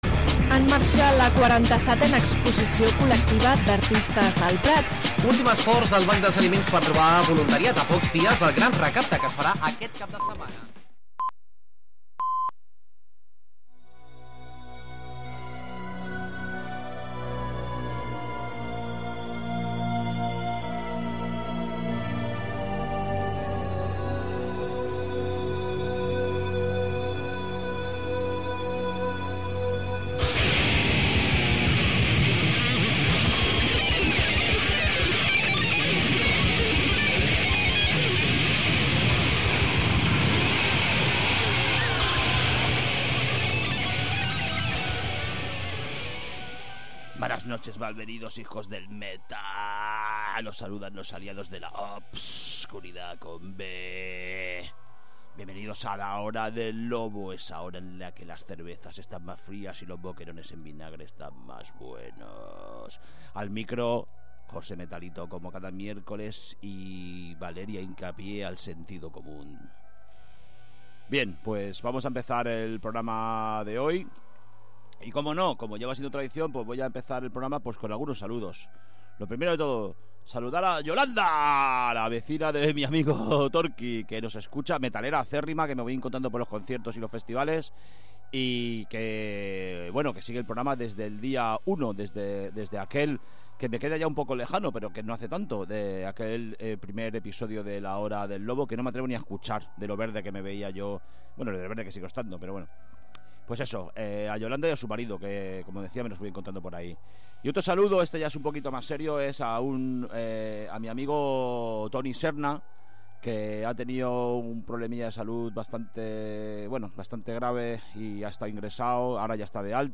A 'La hora del lobo', ens arrossegarem per tots els subgèneres del metal, submergint-nos especialment en les variants més fosques i extremes.